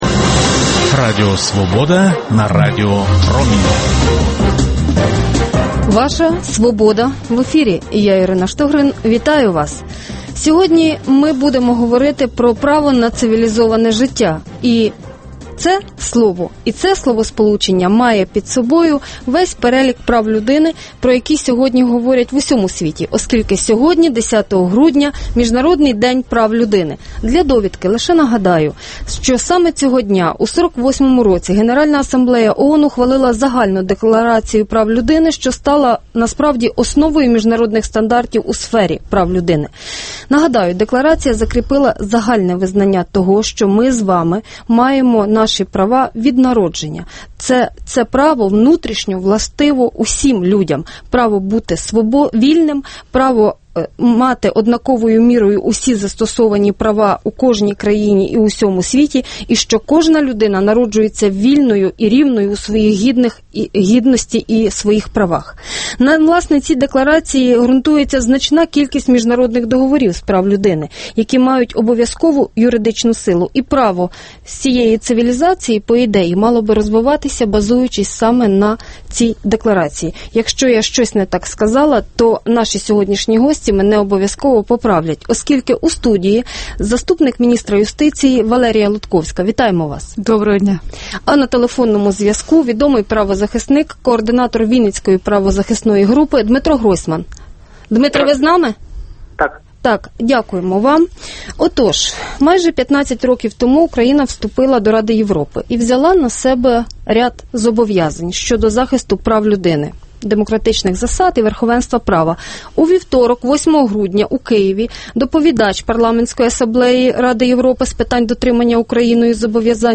Дискусія про головну подію дня